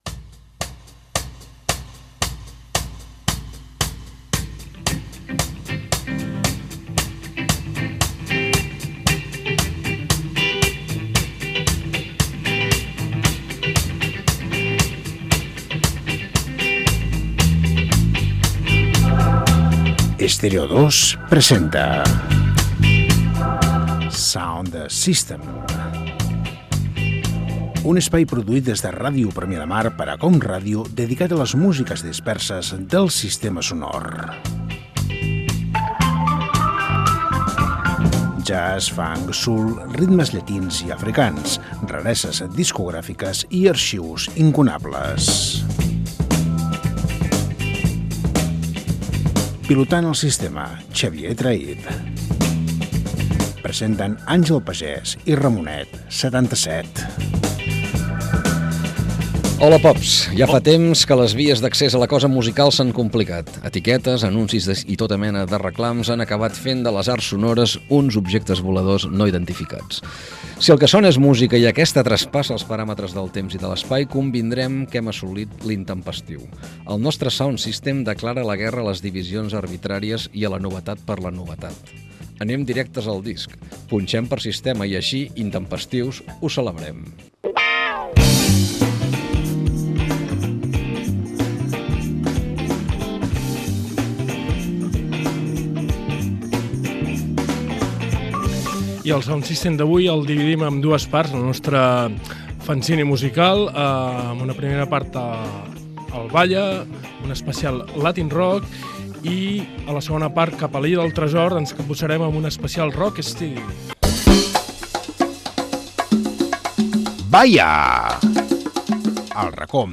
Musical
Presentador/a
FM